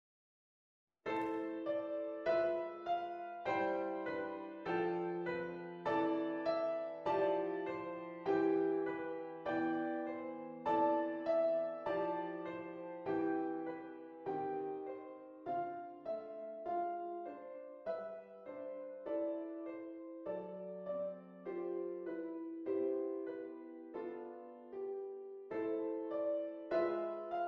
Besetzung: Klavier
Tempo / Satzbezeichnung: Andante
Tonart: C-Dur